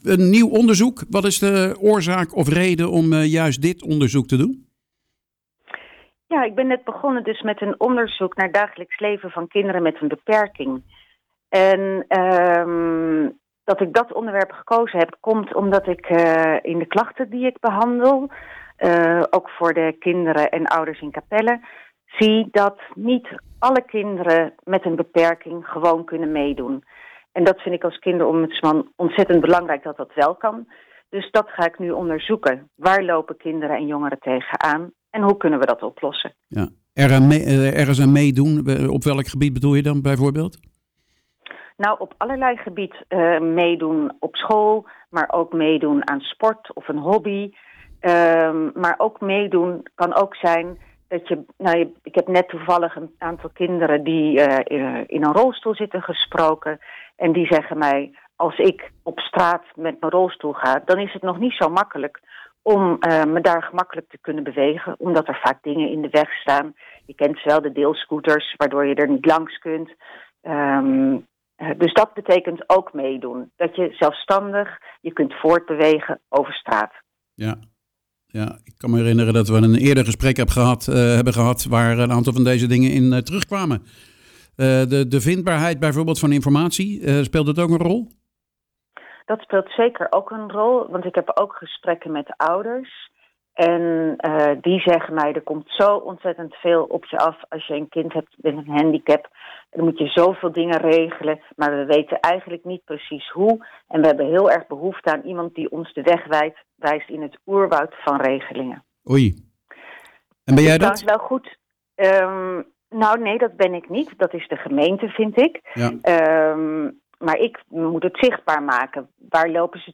praat met Kinderombudsman Stans Goudsmit